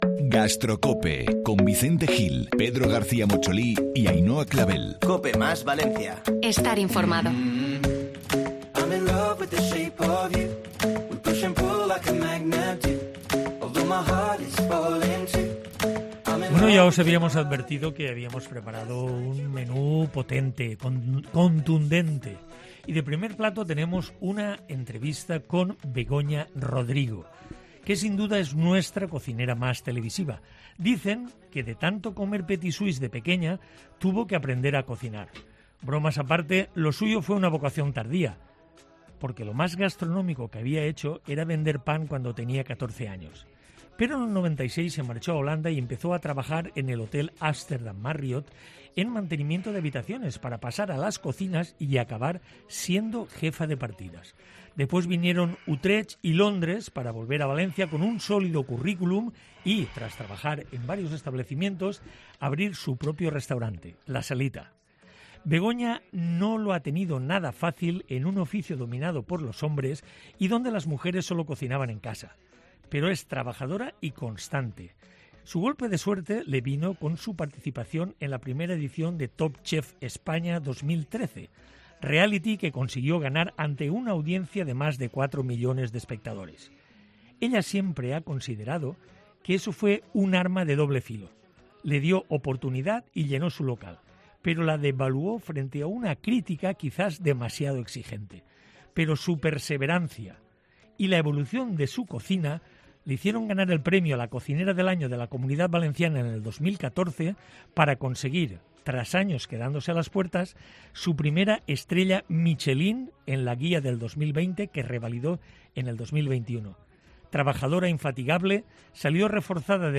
La chef valenciana estrella Michelin Begoña Rodrigo visita los micrófonos de COPE Más Valencia para hablar sin tapujos de la situación actual del sector y de cómo afronta estos momentos de incertidumbre con más de treinta personas a su cargo.